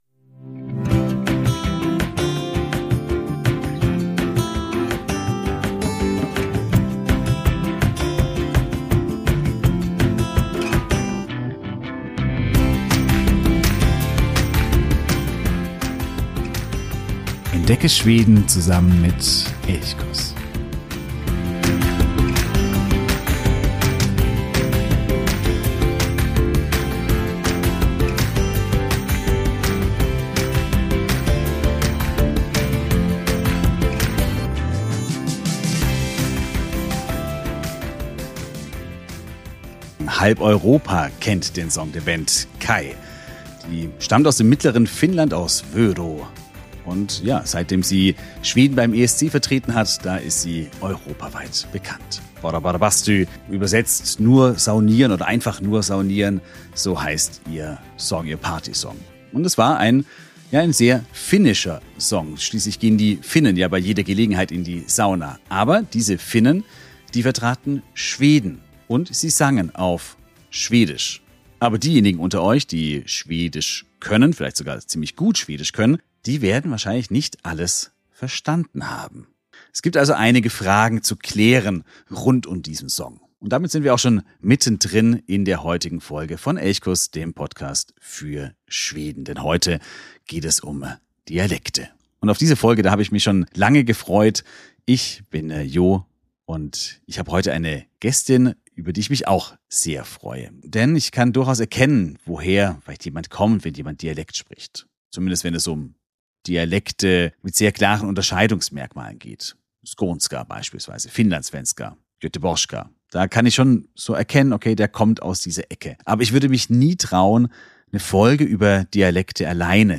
am Ende gibt es noch ein kleines Dialektquiz.